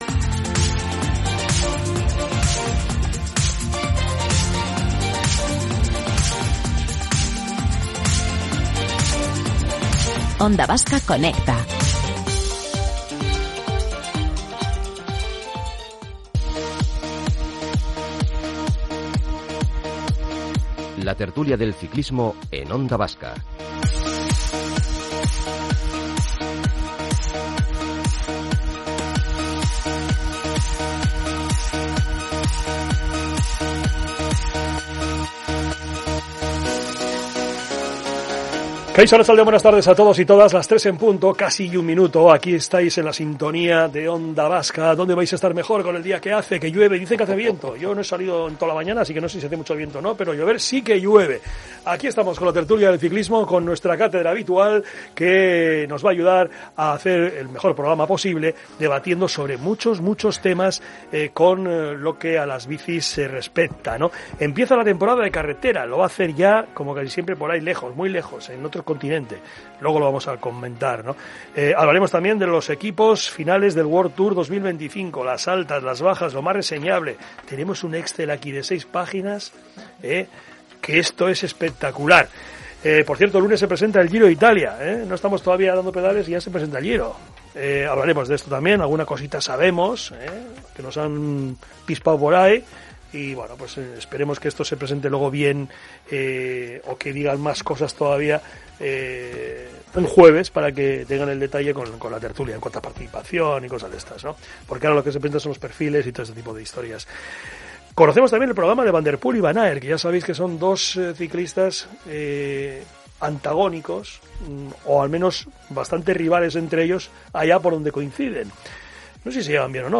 Tertulias